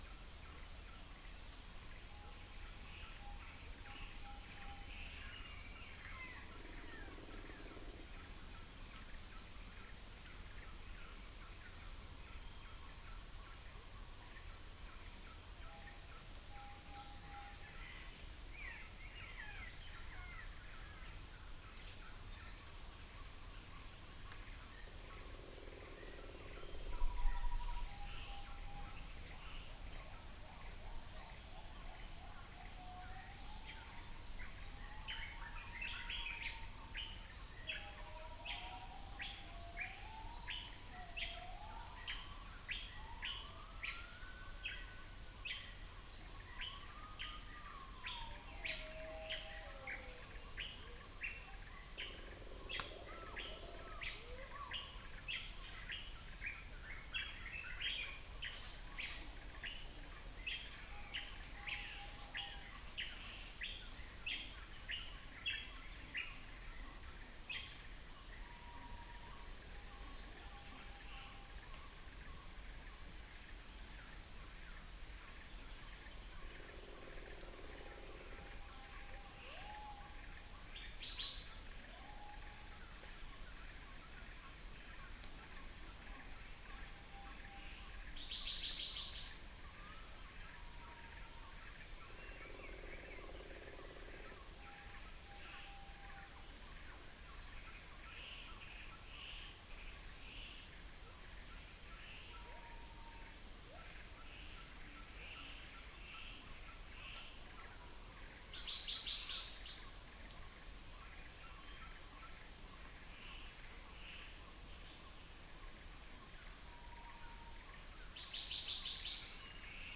3 - amphibian
0 - unknown bird